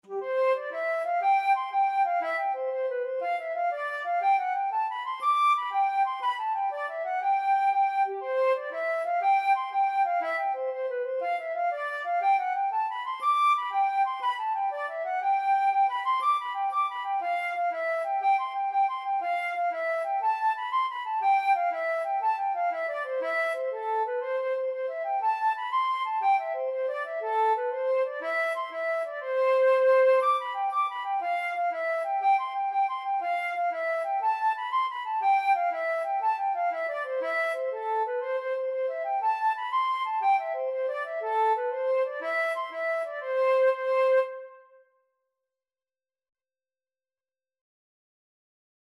Flute version
6/8 (View more 6/8 Music)
C major (Sounding Pitch) (View more C major Music for Flute )
Flute  (View more Easy Flute Music)
Traditional (View more Traditional Flute Music)